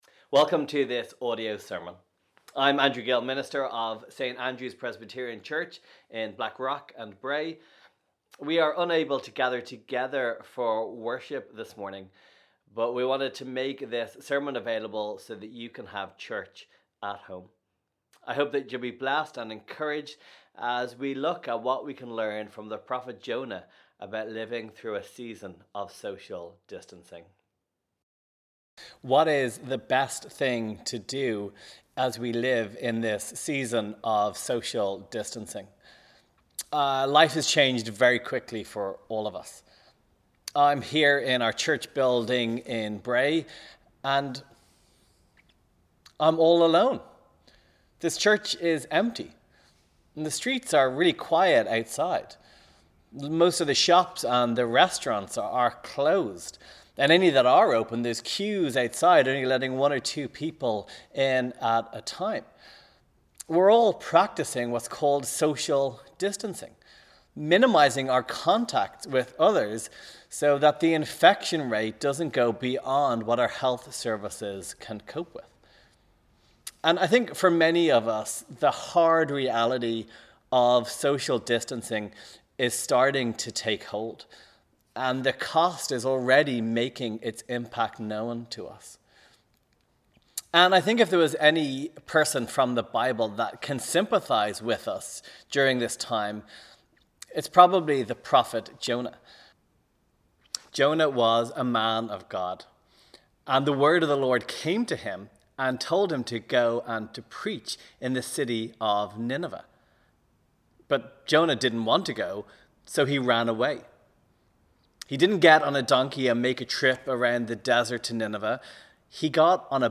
Sermon – “living in the belly of the fish”